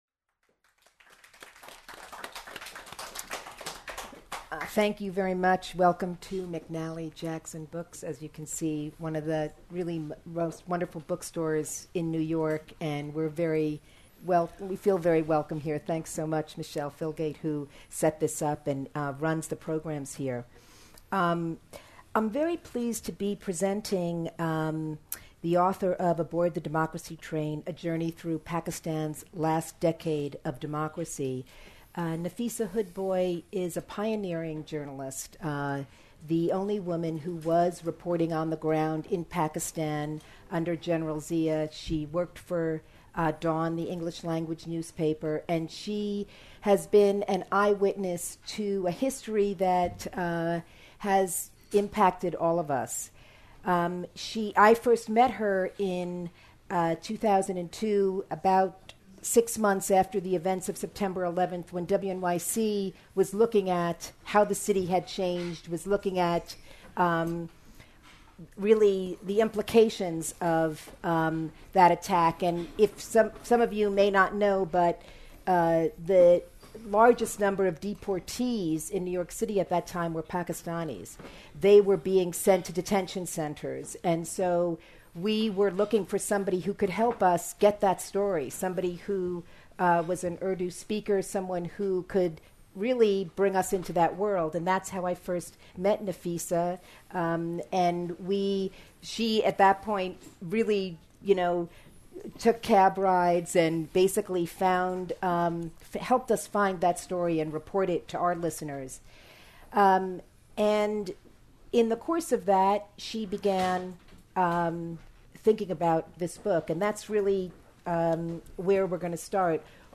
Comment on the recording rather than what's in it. NY Literati Attend ATDT Book Launch on Feb 21 – Aboard The Democracy Train In busy New York city, throbbing with frenetic commercial activity, the Western literati flocked on a cold Tuesday evening into the independent book store, McNally Jackson.